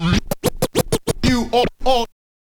scratch_kit01_05.wav